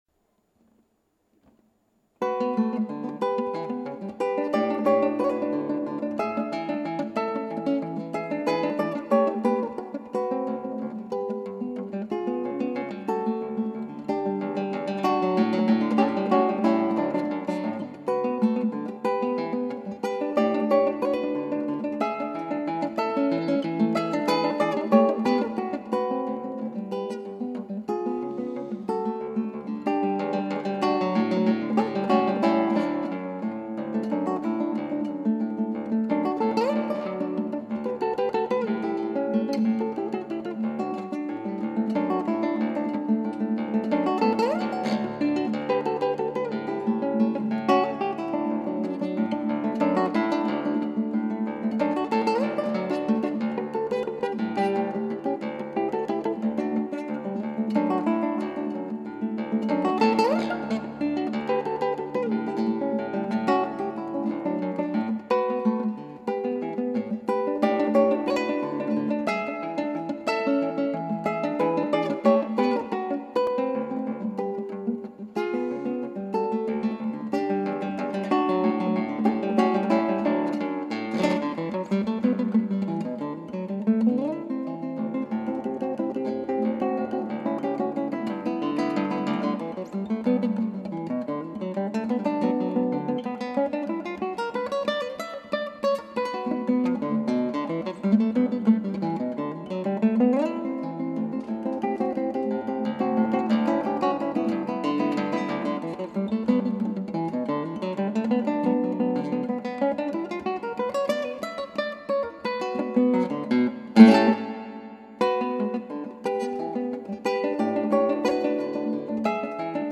クラシックギター　ストリーミング　コンサート
なかなか快調な曲なのですが快調に弾けない曲です。
加えてあのメロディの和音にヴィブラートかけるのは至難の技です。